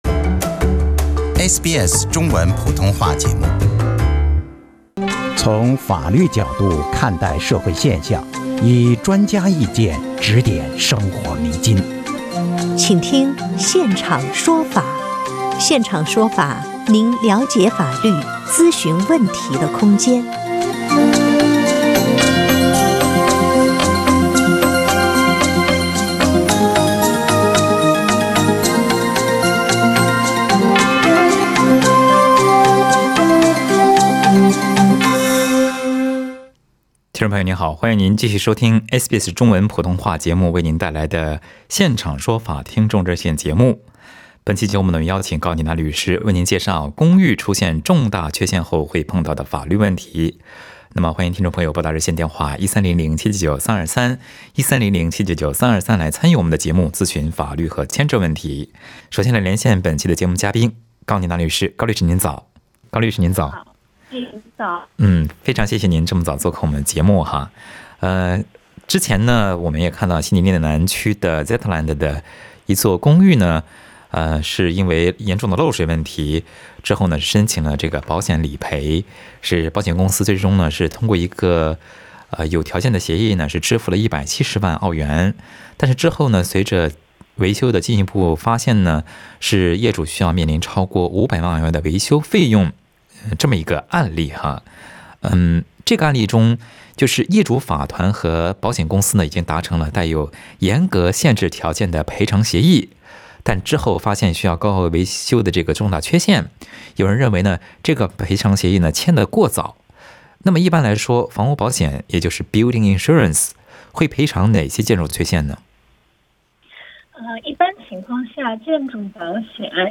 听众朋友也在节目中咨询了公寓碰到的法律问题。